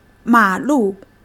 ma3-lu4.mp3